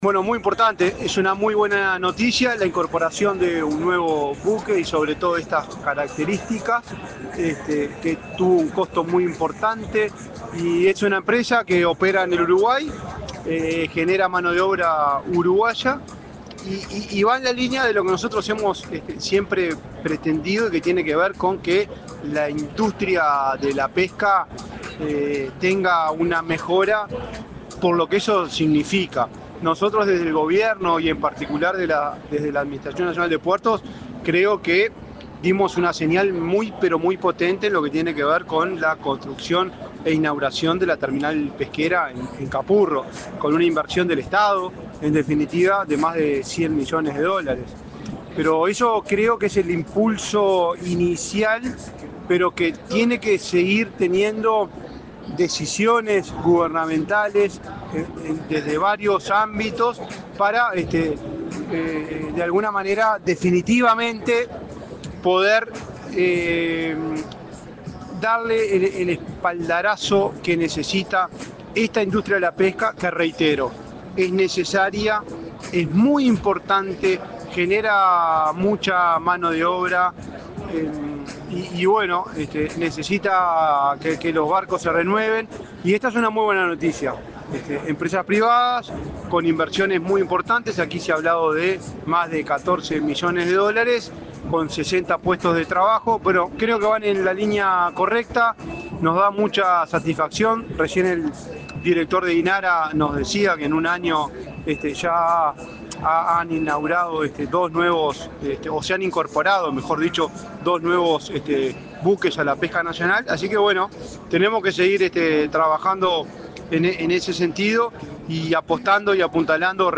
Entrevista al presidente de la ANP, Juan Curbelo
Entrevista al presidente de la ANP, Juan Curbelo 03/12/2024 Compartir Facebook X Copiar enlace WhatsApp LinkedIn El presidente de la Administración Nacional de Puertos (ANP), Juan Curbelo, dialogó con Comunicación Presidencial en el Puerto de Montevideo, donde participó de la ceremonia de bienvenida al buque Río Solís IV de Pesquería Belnova, a la flota nacional.